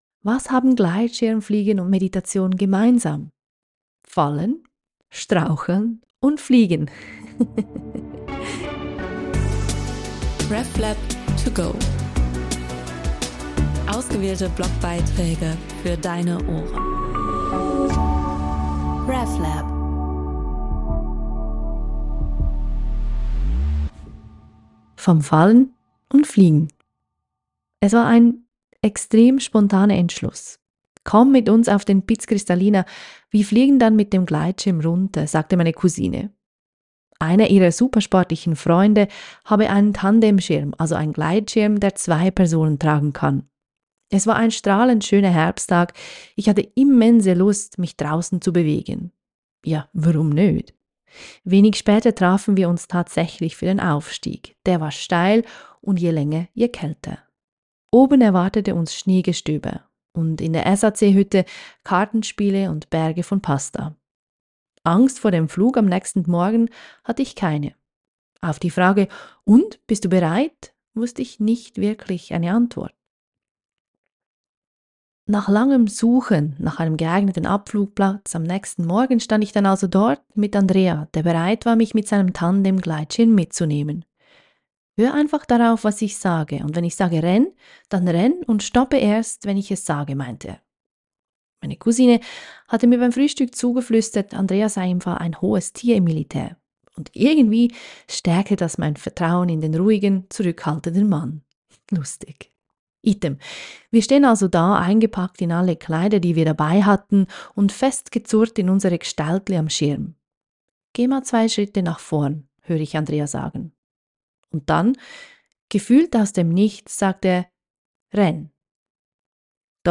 Geschrieben und vorgelesen